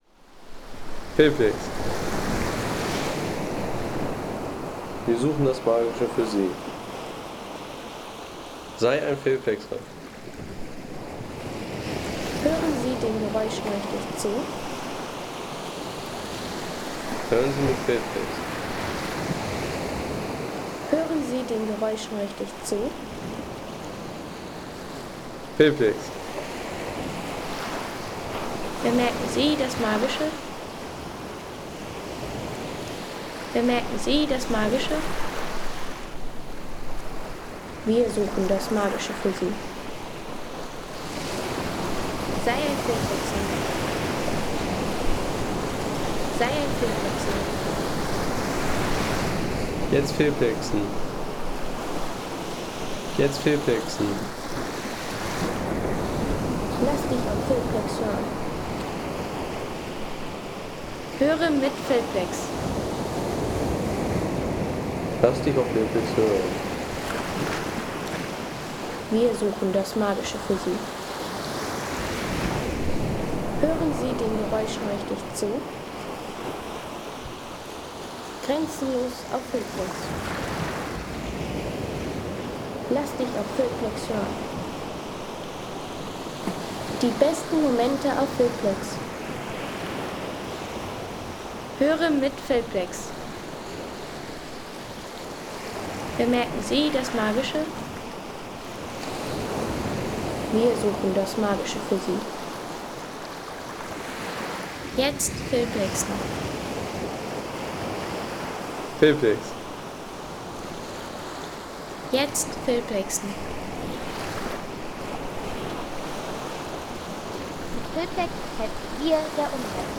Barfuß im Klang der Wellen – Sandhåland
Landschaft - Strände